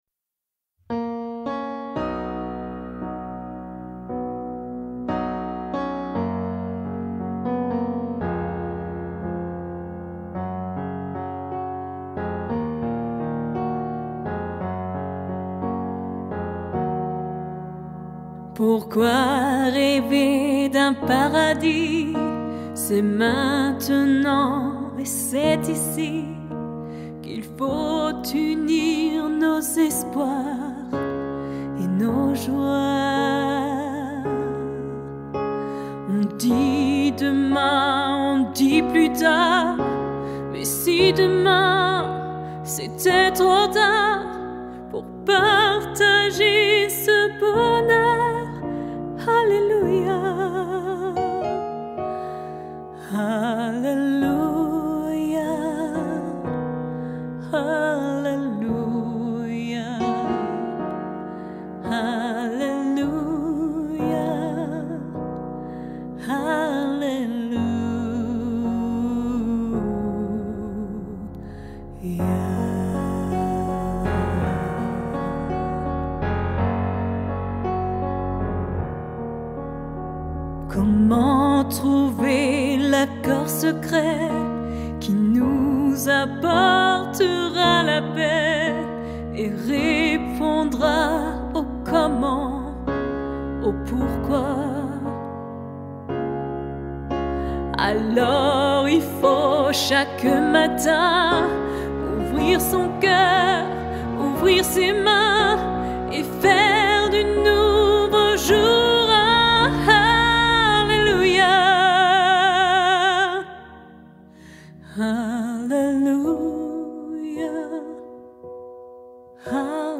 - Œuvre pour chœur à 3 voix mixtes (SAH) a capella